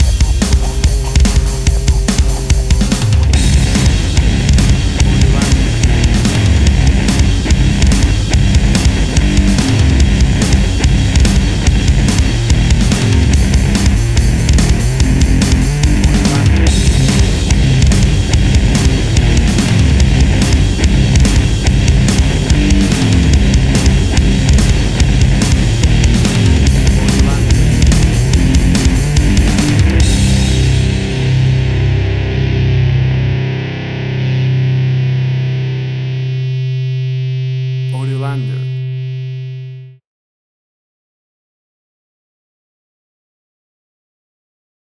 An angry and scary piece of aggressive rock metal.
Tempo (BPM): 144